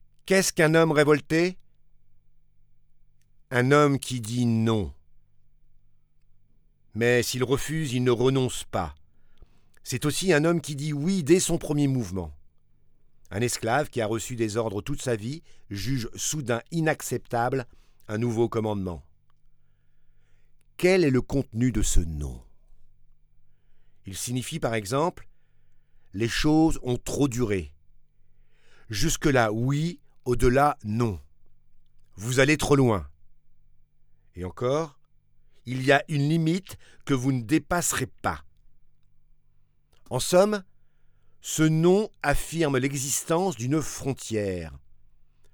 Voix off
45 - 87 ans - Baryton-basse